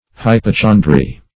Hypochondry \Hy`po*chon"dry\, n.